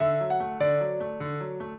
piano
minuet11-6.wav